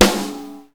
normal-hitclap2.ogg